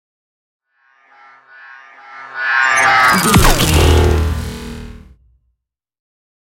Sci fi shot whoosh to hit
Sound Effects
heavy
intense
dark
aggressive
hits